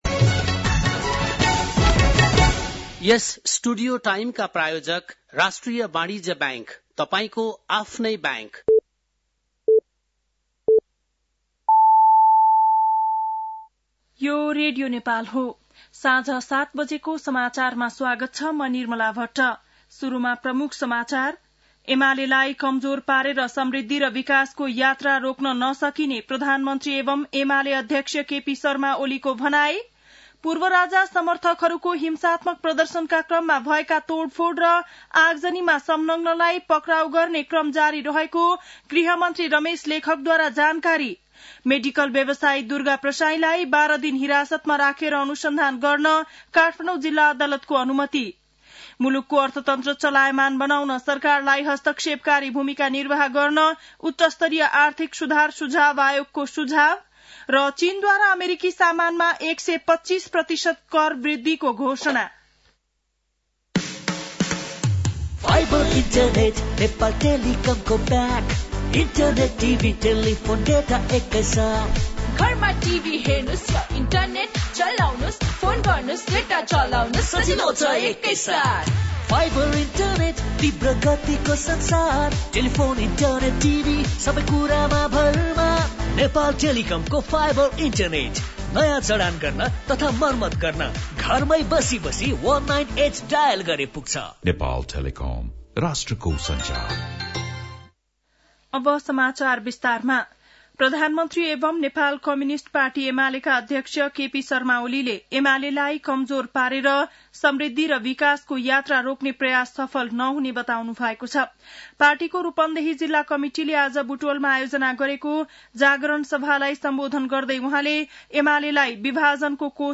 बेलुकी ७ बजेको नेपाली समाचार : २९ चैत , २०८१
7-pm-nepali-news-12-29.mp3